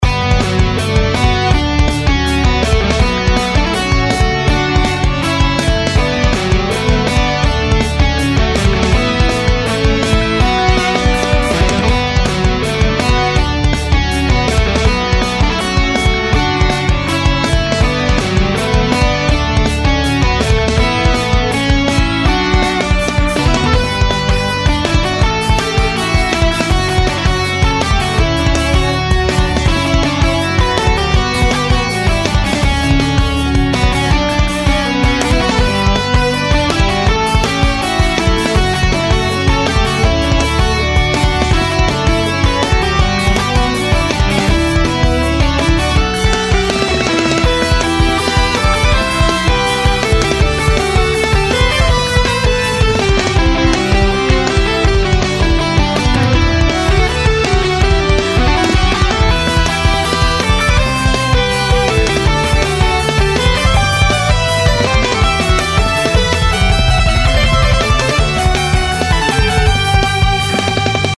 それぞれ１ループの音源です♪
イントロなし